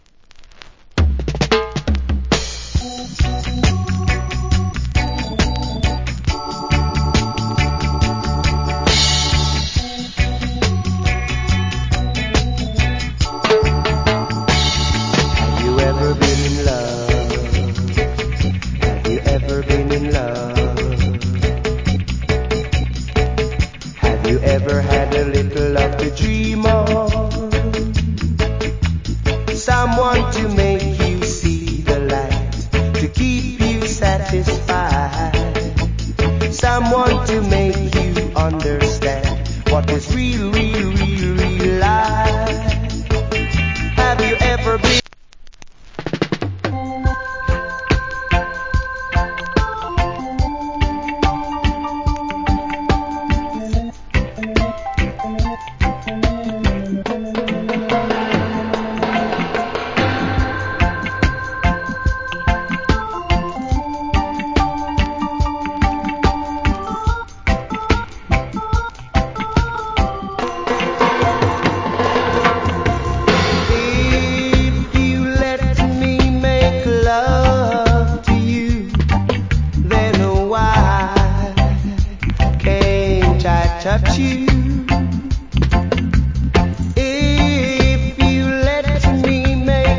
Cool Reggae Vocal + Dub.